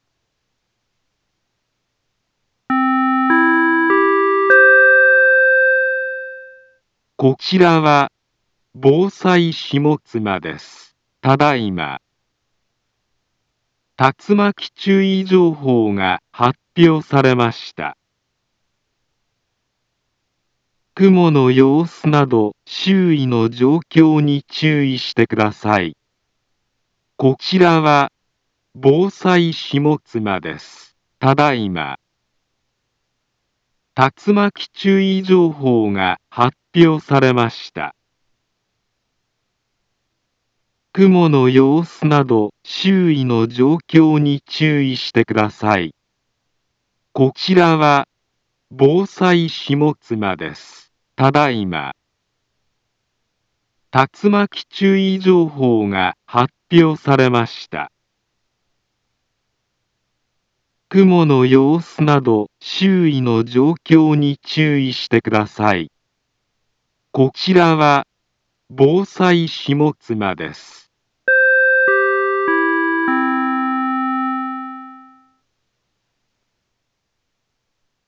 Back Home Ｊアラート情報 音声放送 再生 災害情報 カテゴリ：J-ALERT 登録日時：2025-09-03 17:44:36 インフォメーション：茨城県北部、南部は、竜巻などの激しい突風が発生しやすい気象状況になっています。